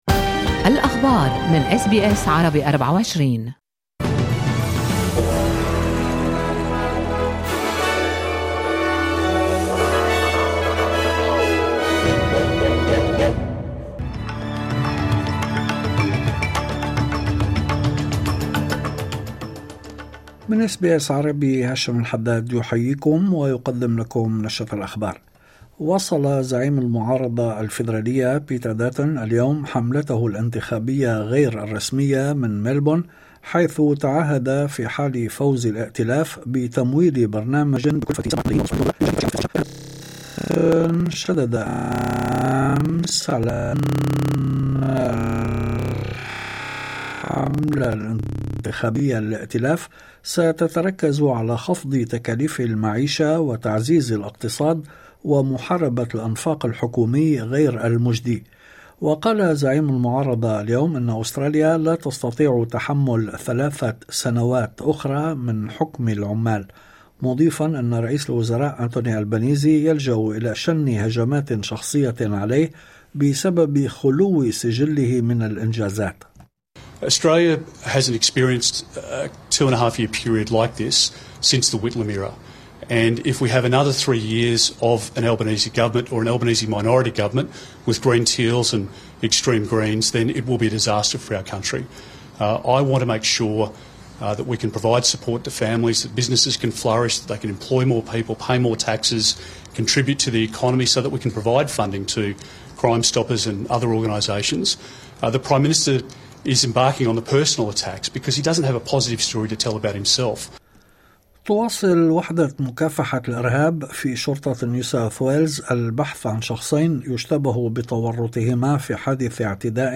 نشرة أخبار الظهيرة 13/01/2025